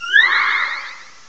cry_not_slurpuff.aif